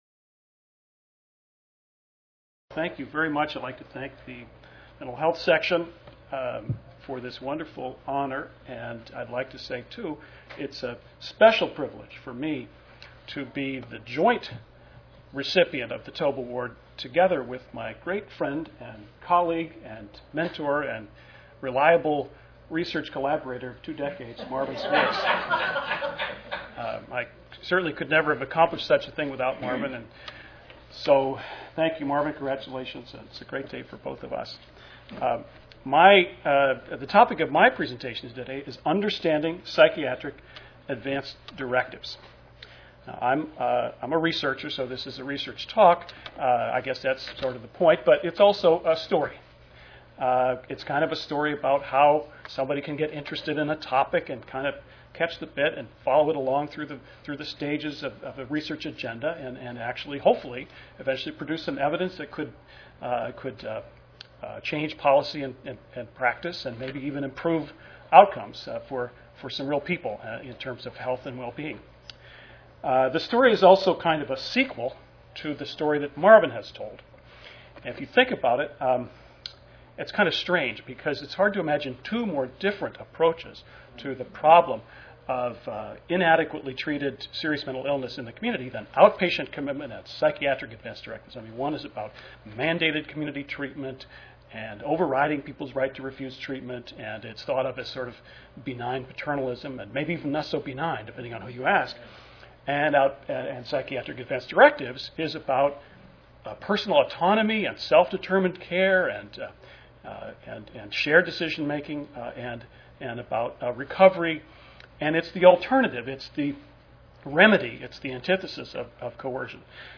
Carl Taube award lecture